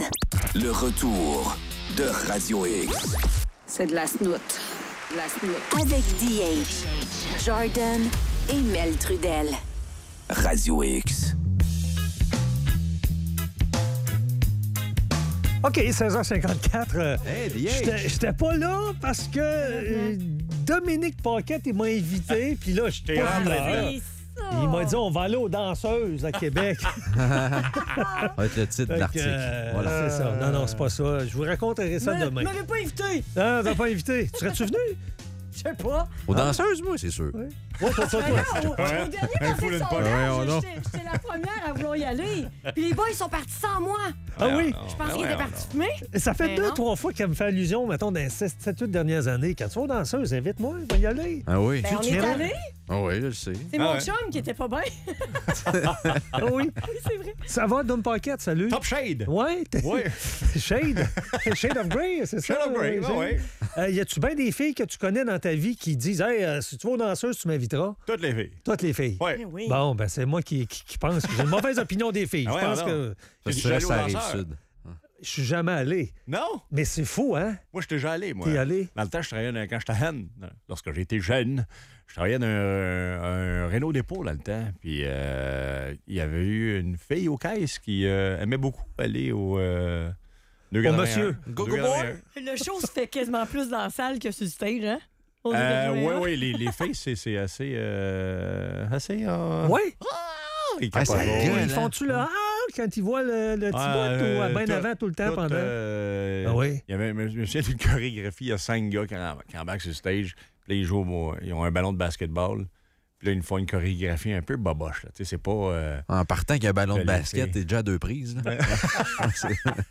Entrevue avec Dominic Paquet.